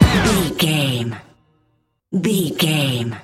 Epic / Action
Fast paced
Ionian/Major
Fast
synthesiser
drum machine
80s